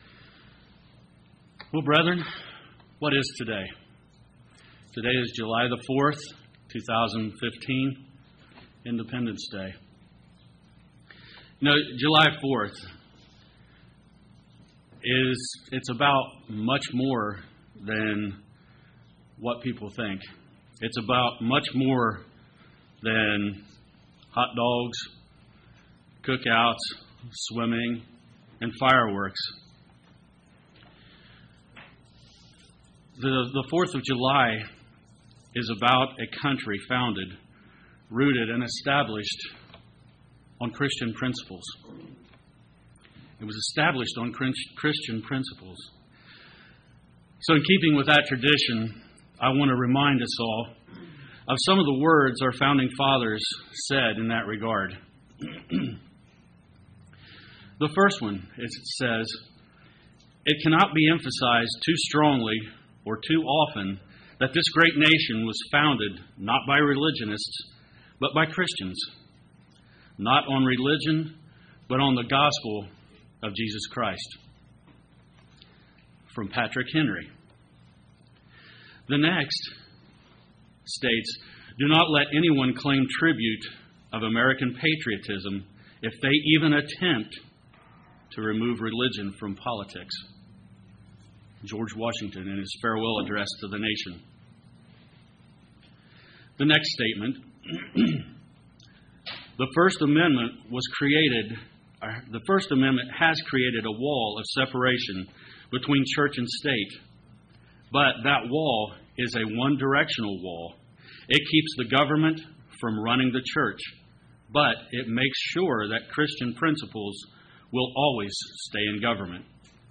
This sermon asks many questions.